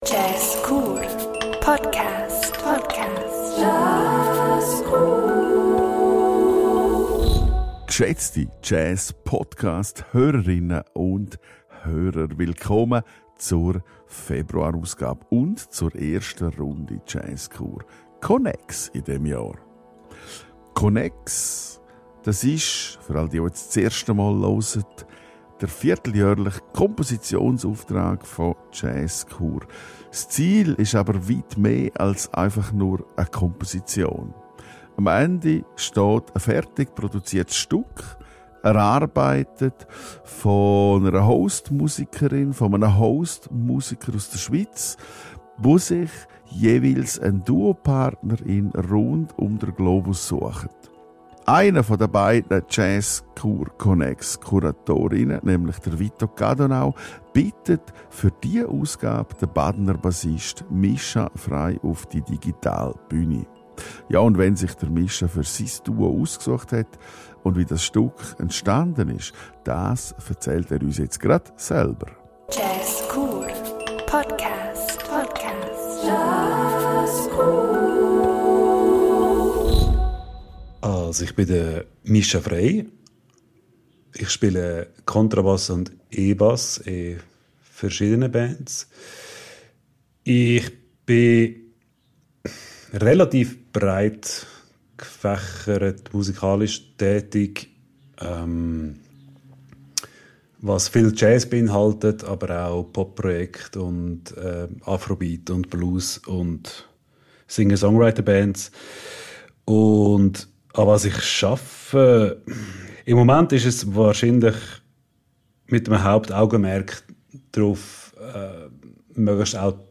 Und zum Schluss lädt er euch alle in einen Raum voll mit Bass und Stimme ein.